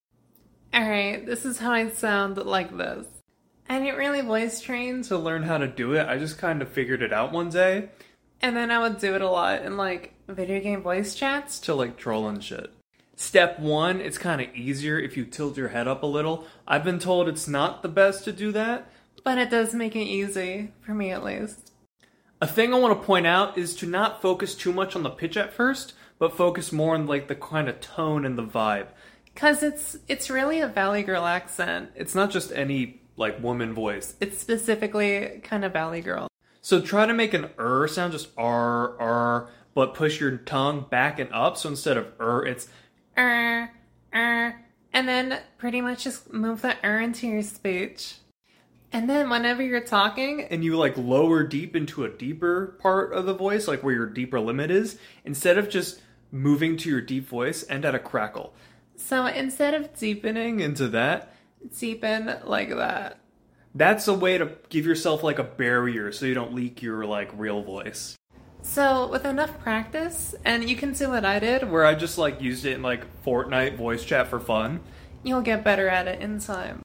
Female Voice tutorial